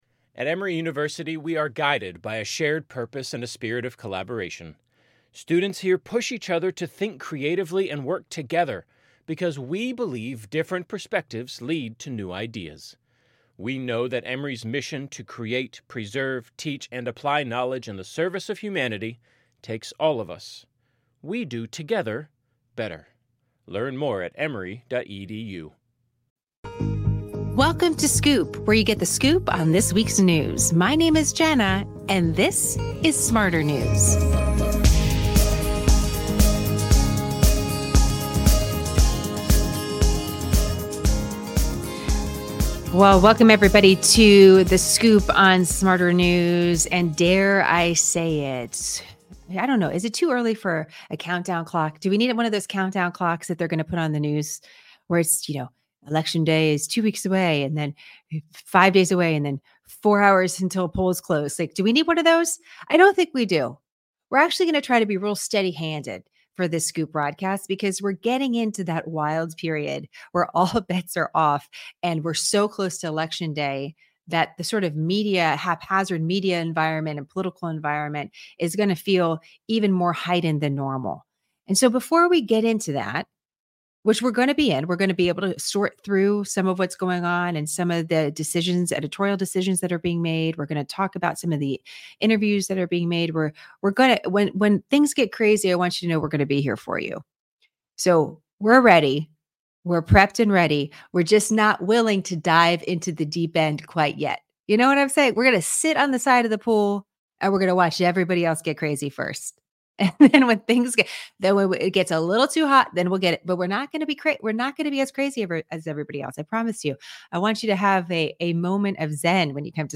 ✓ 13:00: We hear from Democratic Political Strategist, David Plouffe.
✓ 25:00: Two soundbites from the 60 Minutes Interview with VP Kamala Harris.